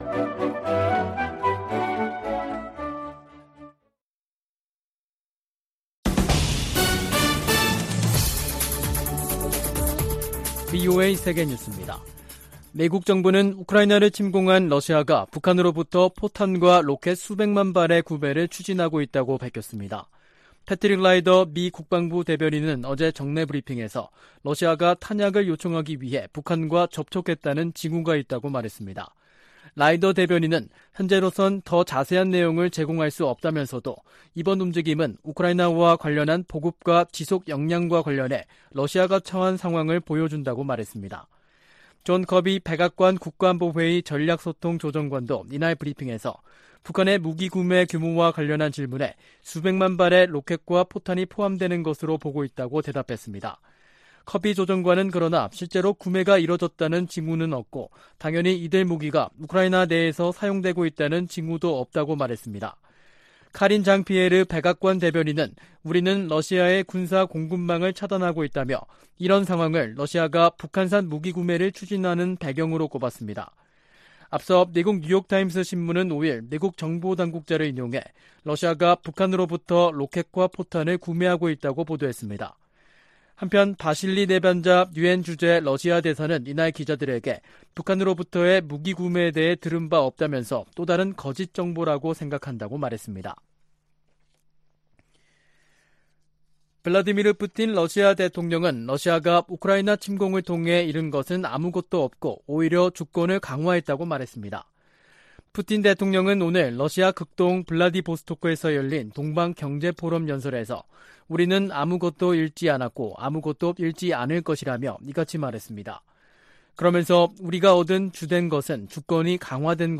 VOA 한국어 간판 뉴스 프로그램 '뉴스 투데이', 2022년 9월 7일 2부 방송입니다. 미국과 한국, 일본 북핵 수석대표들이 북한의 도발에 단호히 대응하겠다고 거듭 강조했습니다. 미국 정부가 러시아의 북한 로켓과 포탄 구매에 대해 유엔 안보리 결의 위반이라고 지적했습니다. 유엔이 강제실종과 관련해 북한에 총 362건의 통보문을 보냈지만 단 한 건도 응답하지 않았다며 유감을 나타냈습니다.